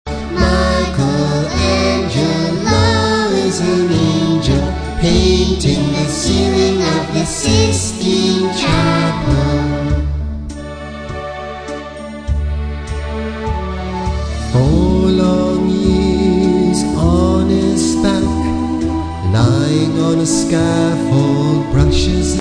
young children